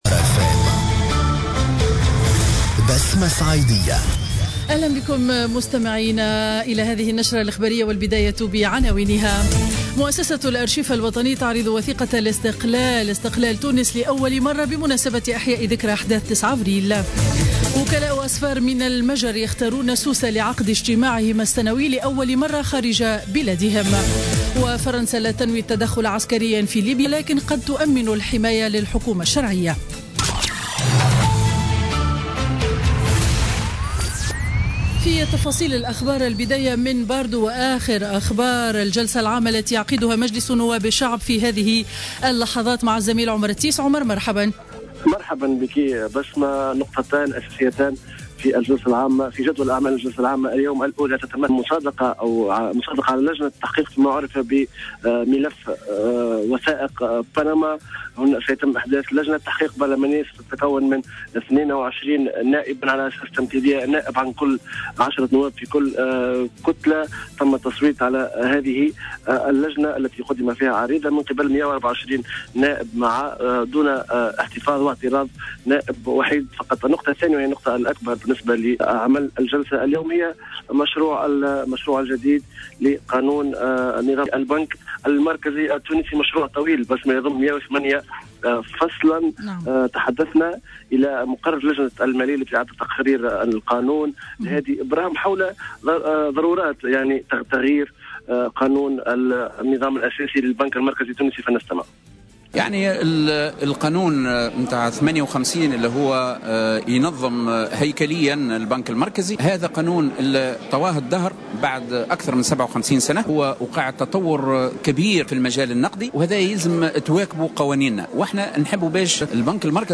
نشرة أخبار منتصف النهار ليوم الجمعة 8 أفريل 2016